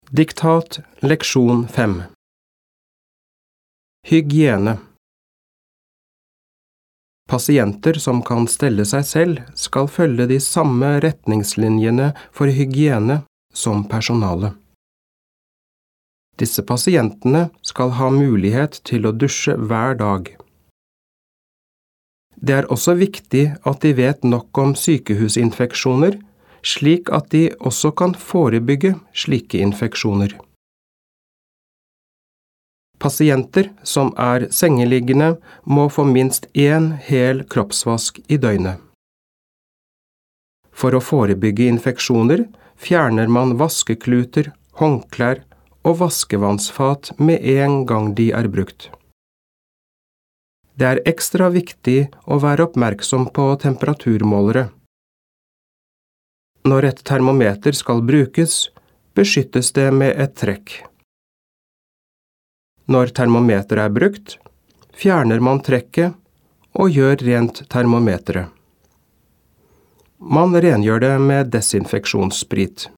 Diktat leksjon 5
• Første gang leses hele teksten, og du skal bare lytte.